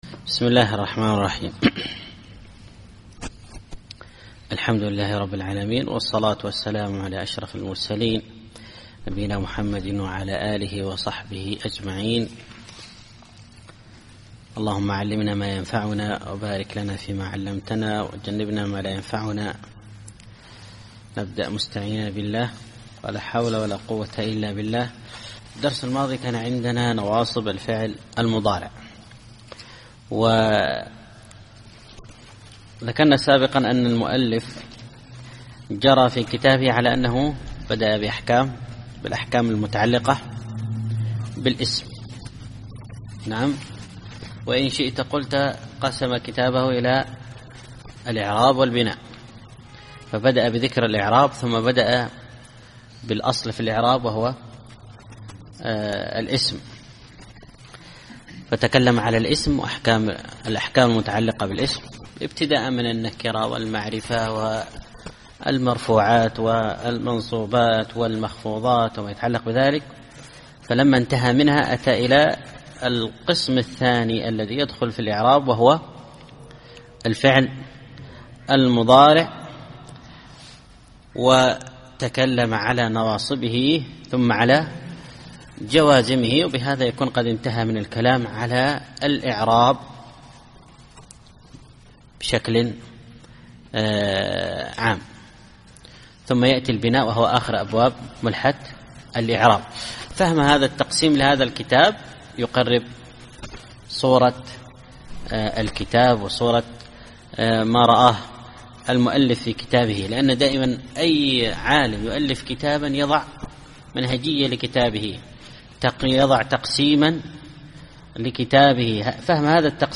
الدرس الحادي والثلاثون الأبيات 342-359